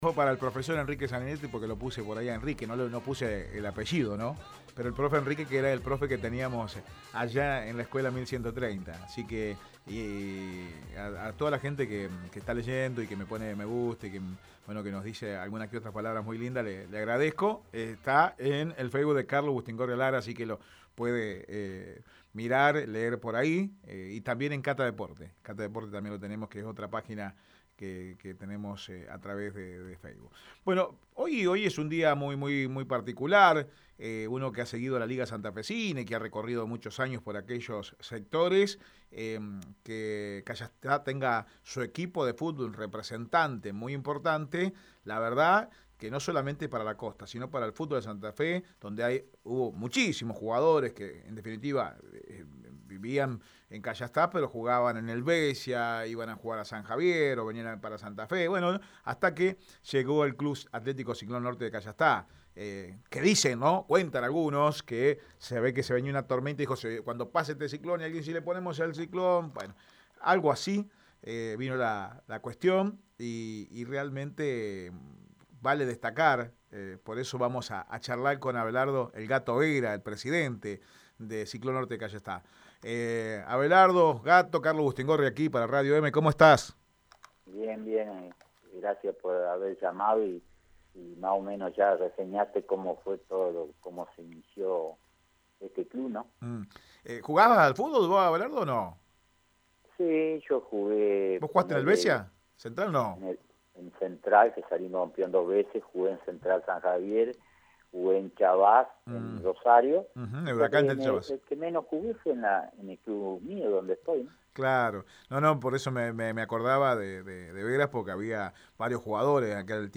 En diálogo con Radio Eme Deportivo